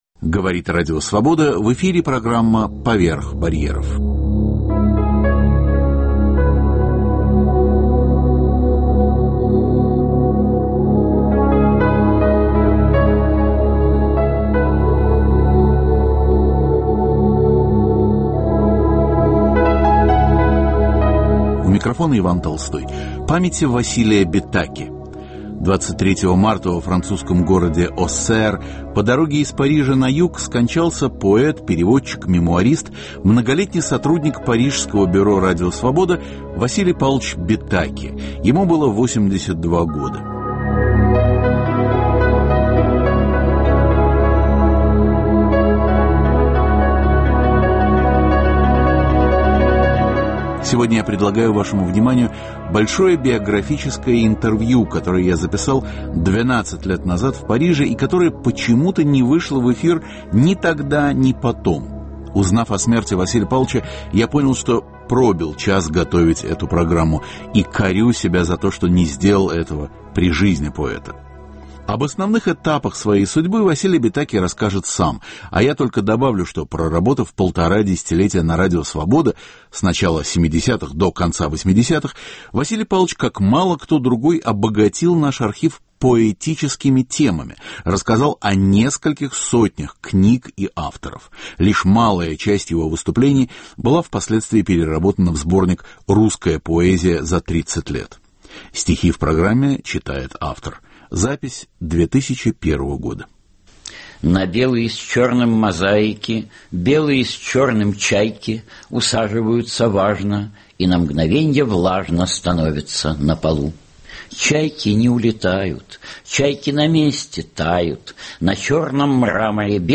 Памяти поэта, переводчика, ветерана Радио Свобода Василия Бетаки. Звучит большое биографическое интервью, записанное в 2001 г. и прежде не выходившее в эфир.